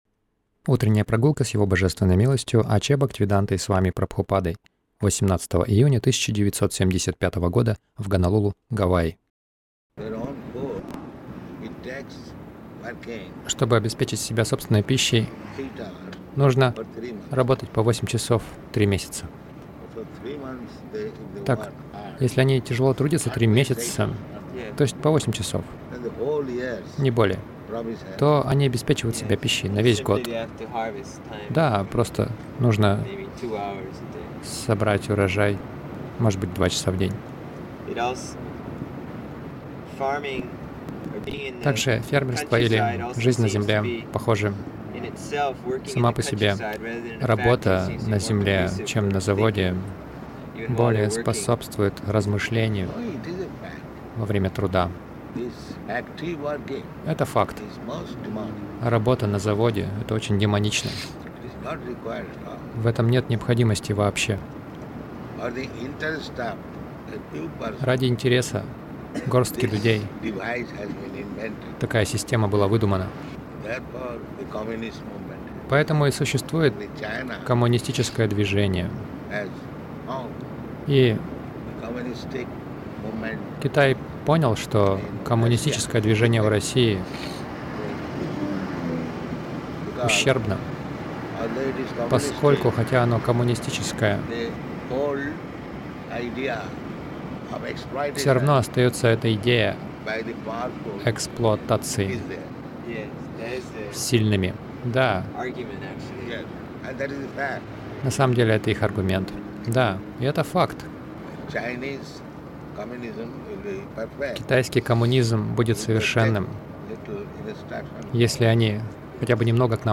Милость Прабхупады Аудиолекции и книги 18.06.1975 Утренние Прогулки | Гонолулу Утренние прогулки — Чем привлекаются люди Загрузка...